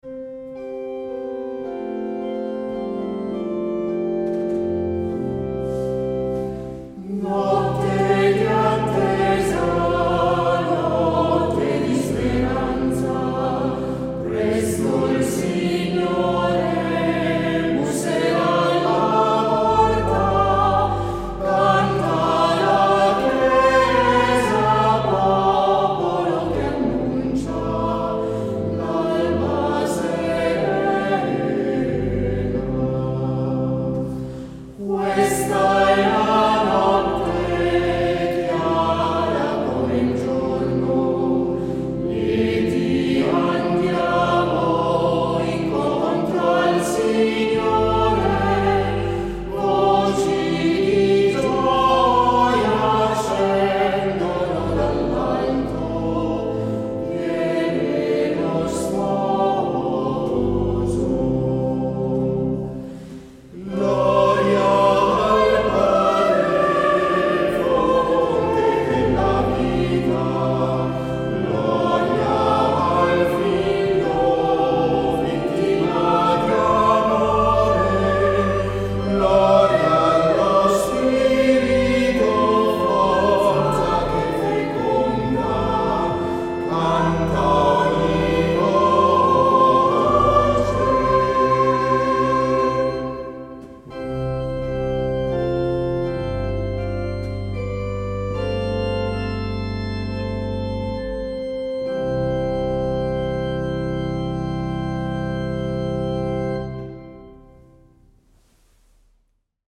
Versione con discanto
Notte-di-attesa-discanto-Mezzalira.mp3